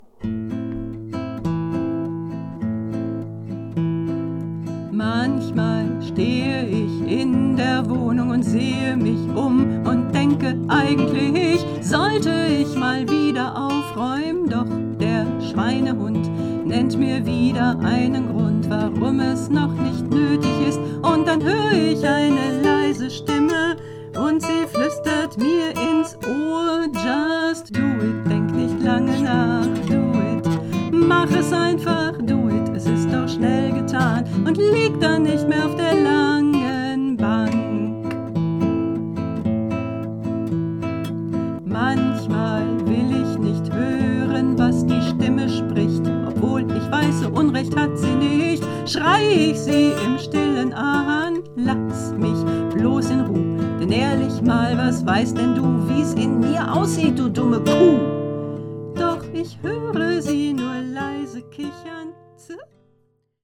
In den vergangenen Jahren sind einige Lieder zu mir gekommen, in denen ich Themen des Lebens besinge.
Gitarre Kopie